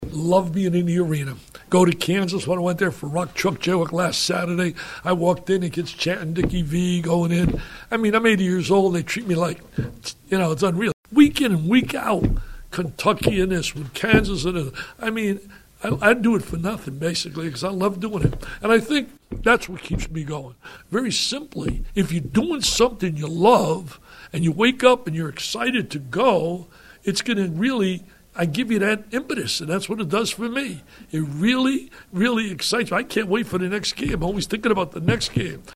Vitale met with the media prior to the banquet. The 80-year-old who is in 14 different halls of fame, spoke about many things, including what motivates him to continue doing what he does.